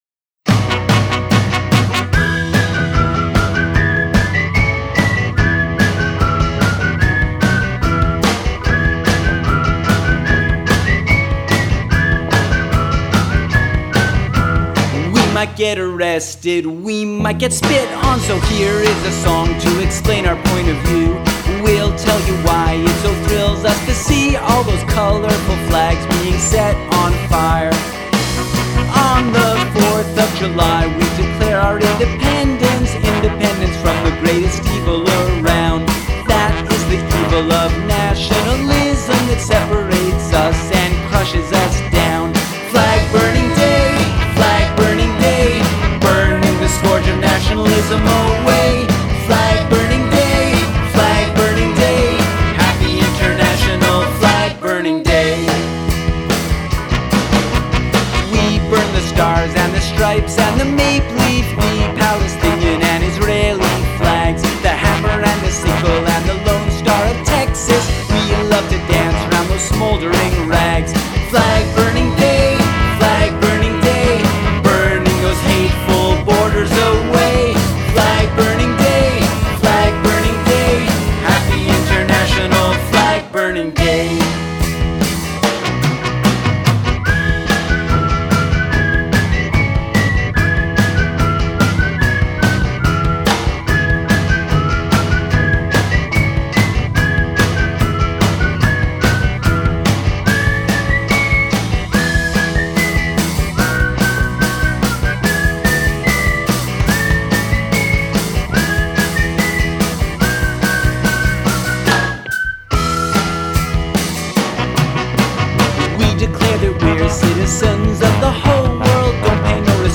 To celebrate, I’m posting this fantastic anthem (